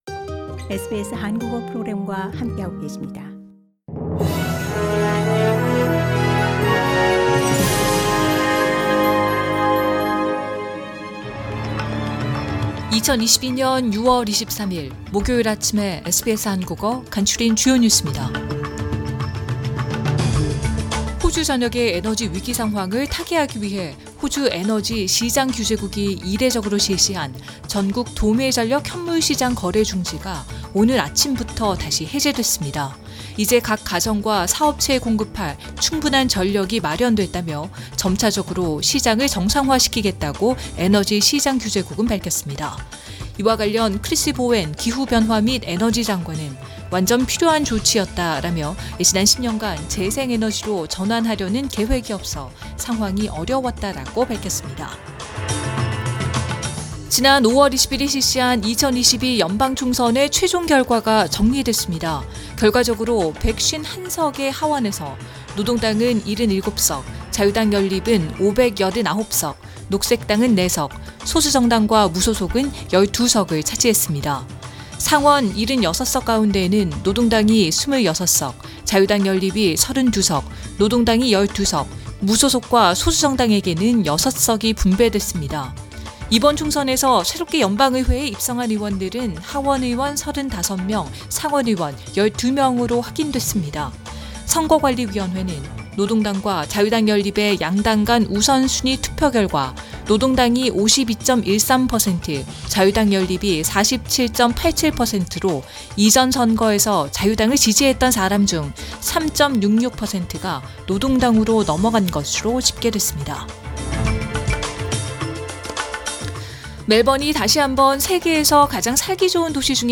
SBS 한국어 아침 뉴스: 2022년 6월 23일 목요일